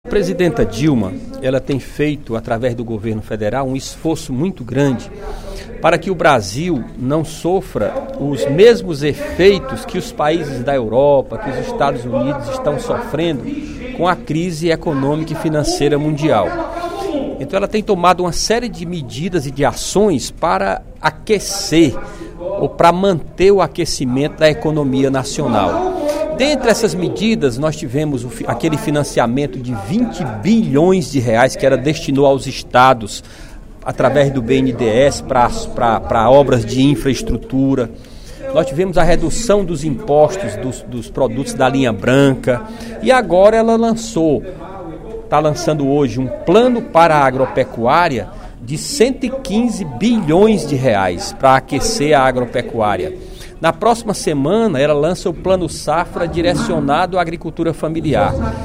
O deputado Nelson Martins (PT) enalteceu, nesta quinta-feira (28/06) em plenário, o lançamento pela presidente Dilma Rousseff de mais um pacote de medidas de estímulo ao crescimento da economia brasileira da ordem de R$ 8,4 bilhões.